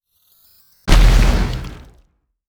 Grenade7.wav